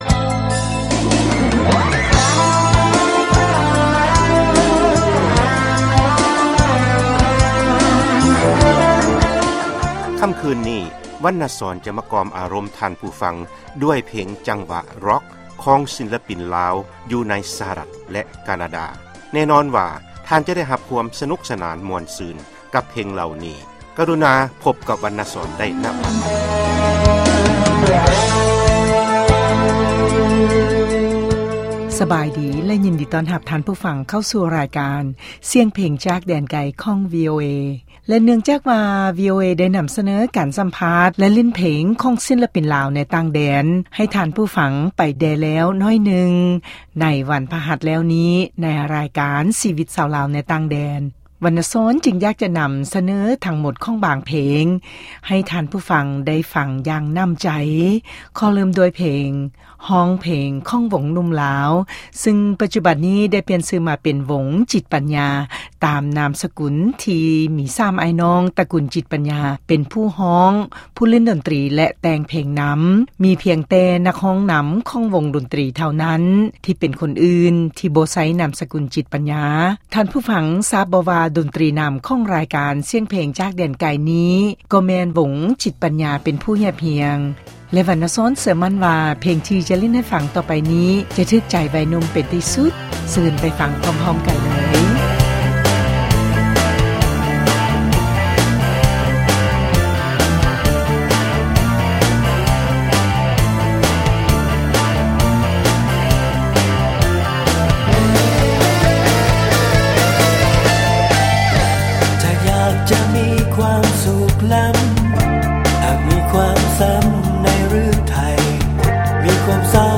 ຖືກໃຈໄວໜຸ່ມຊາວຣ໊ອກ